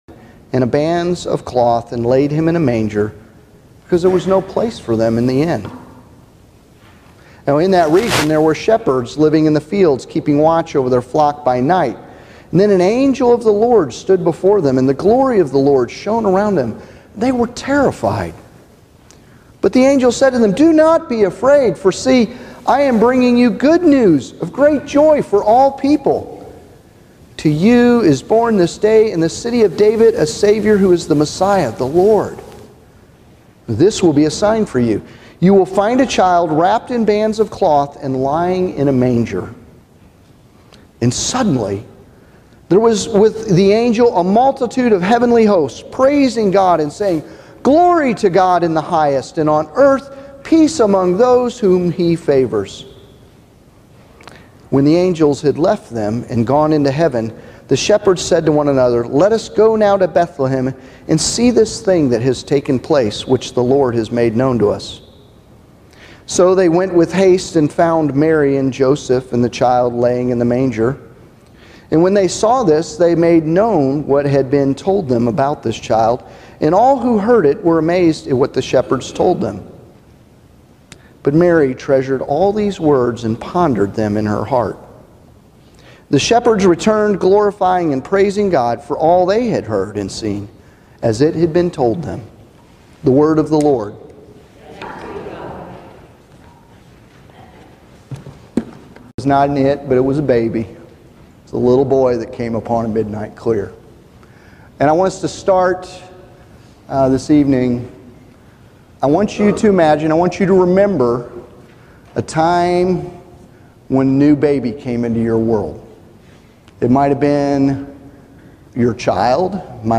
The text in the video is missing a few moments of the gospel reading at the beginning.